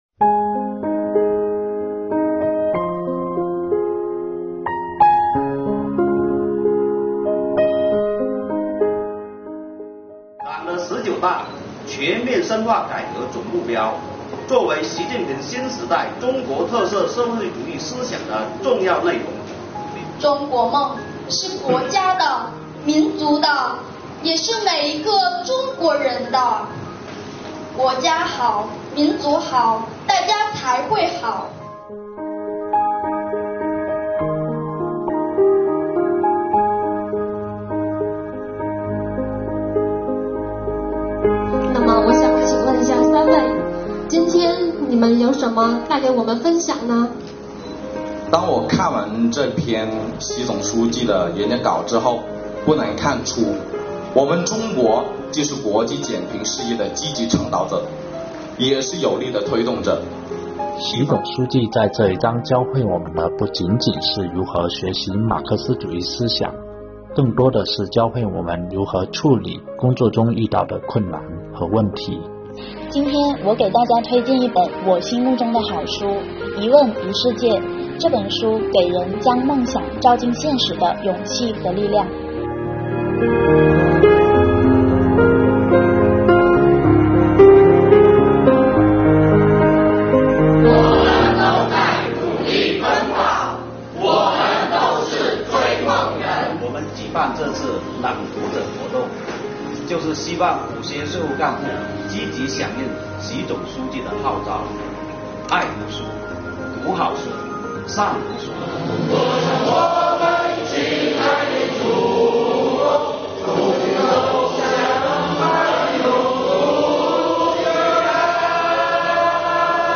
全体人员朗诵《我们都是追梦人》。